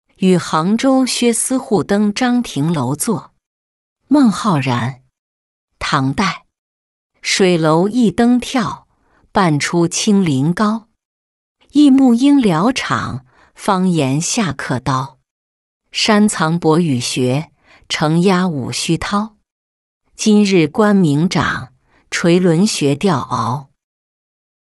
与杭州薛司户登樟亭楼作-音频朗读